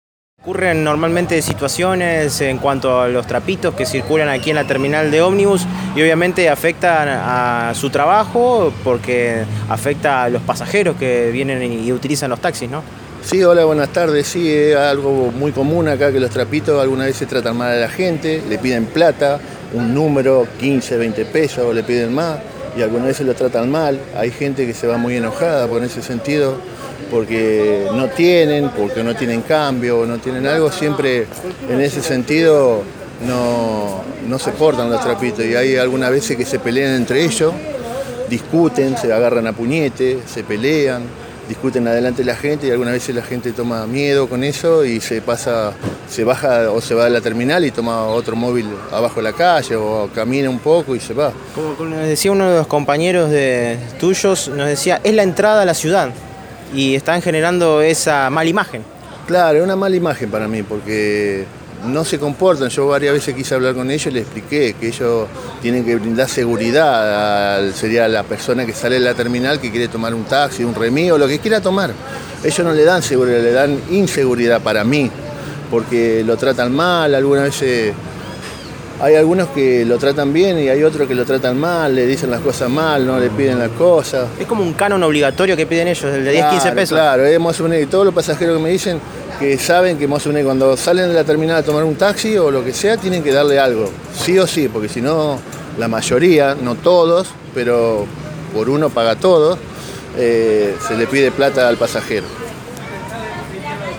Los taxistas, se mostraron indignados con el móvil de Radio EME, reclamando que todos los días son amedrentados por los mismos, que le solicitan dinero para abrirles las puertas, además de diversos desmanes.